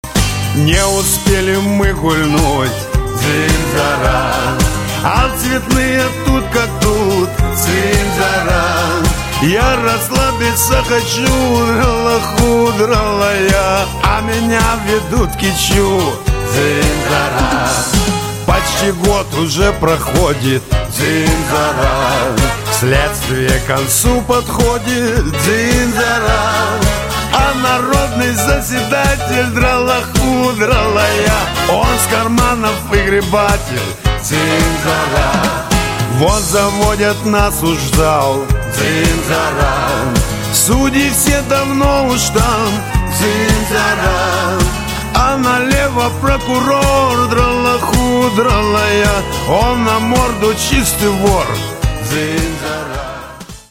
русский шансон
Блатняк